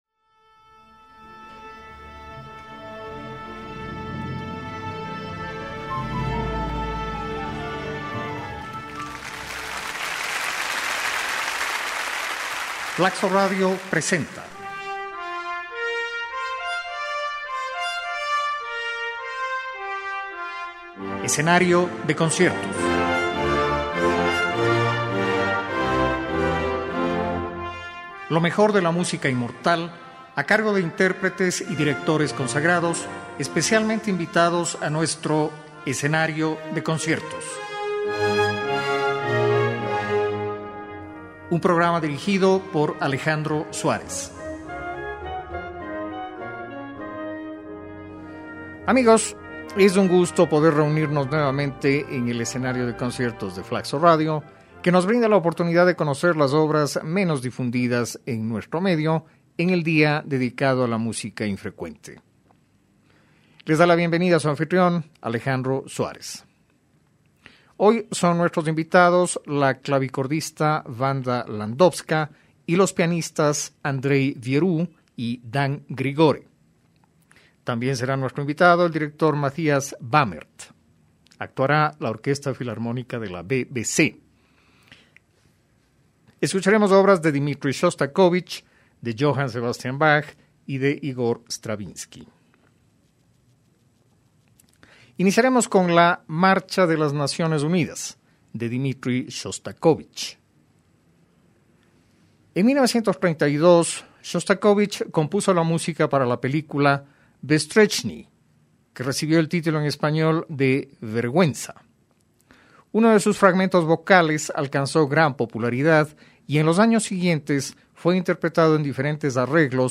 versión para dos pianos